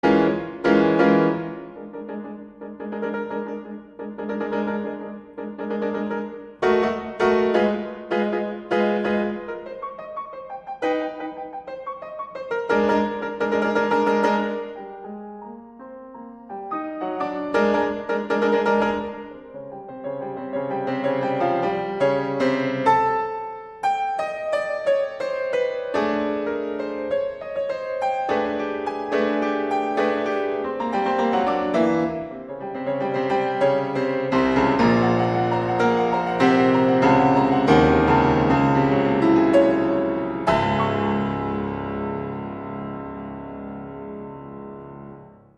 C0RSO DI PIANOFORTE